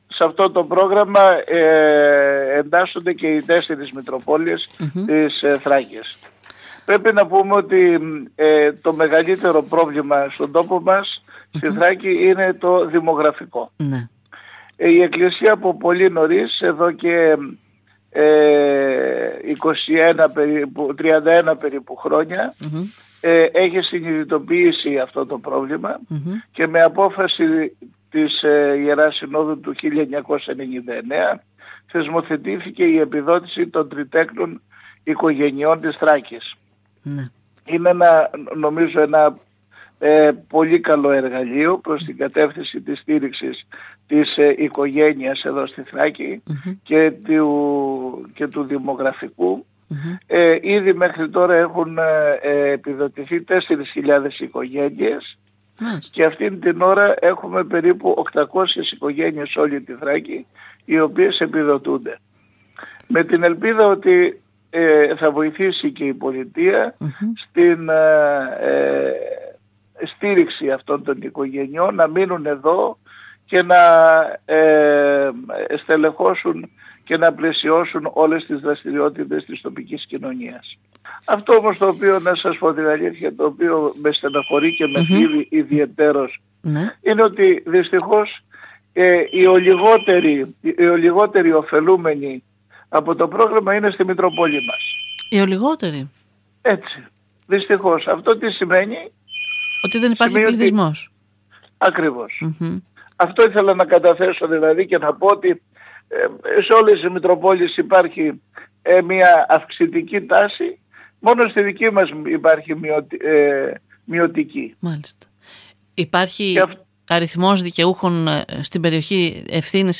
Ο Μητροπολίτης Διδυμοτείχου Ορεστιάδας και Σουφλίου κ. Δαμασκηνός μιλώντας σήμερα στην ΕΡΤ Ορεστιάδας ανέφερε πως «πρόκειται για ένα καλό εργαλείο προς την κατεύθυνση στήριξης της οικογένειας στη Θράκη.»